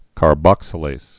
(kär-bŏksə-lās, -lāz)